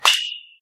金属バットでボールを打つ 着信音
高校野球で使用する金属バットでボールを打った時の音です。・・・少し鈍い音。